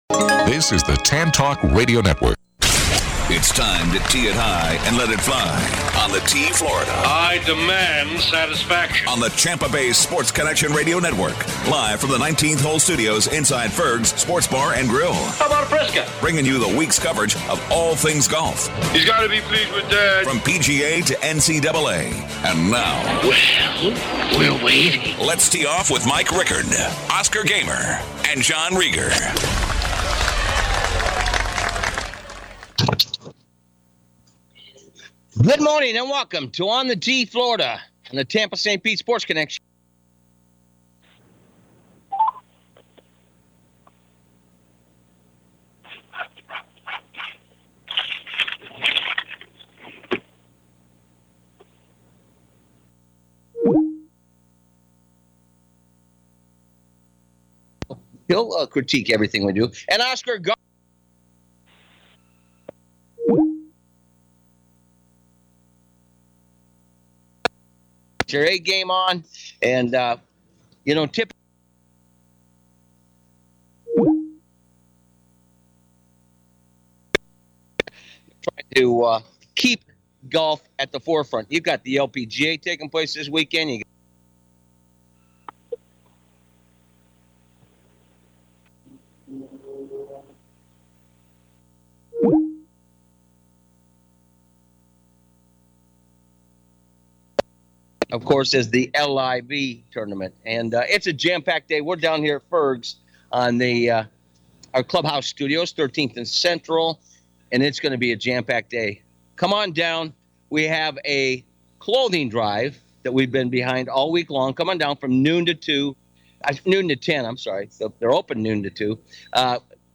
Live from Ferg's